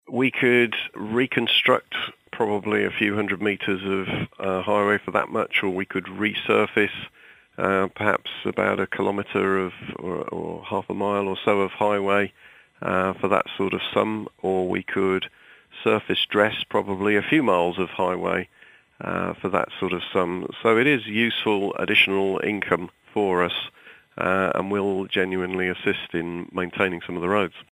Director of Highways Richard Pearson told us exactly what the money could be spent on: